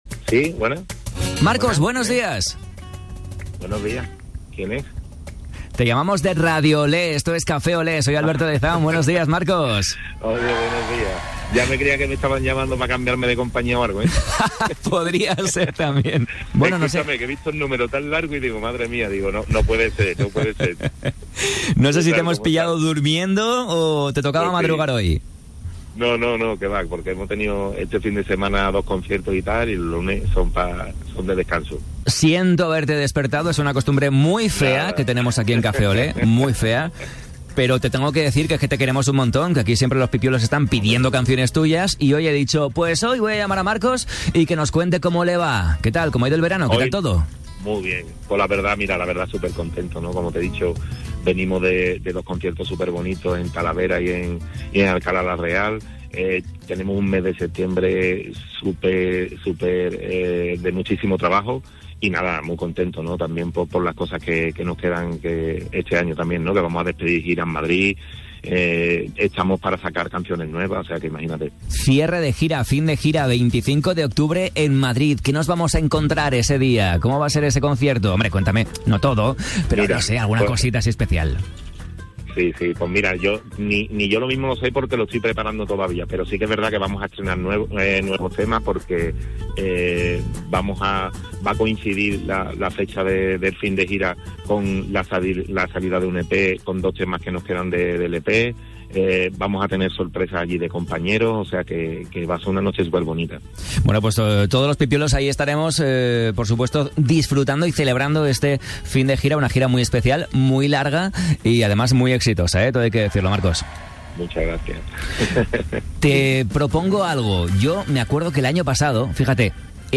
¿Qué mejor que que nos lo cante a primera hora de la mañana?